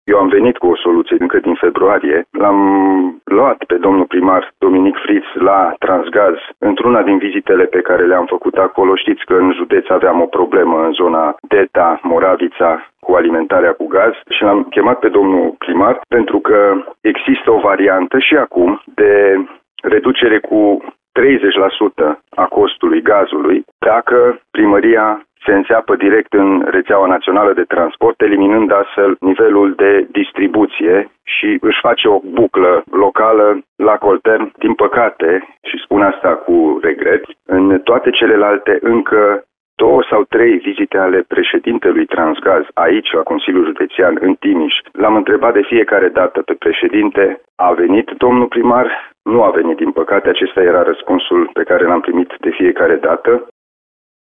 Liderul CJ Timiș a explicat, la Radio Timișoara, că soluția pentru reducerea tarifelor i-a fost prezentantă primarului Dominic Fritz, încă din februarie.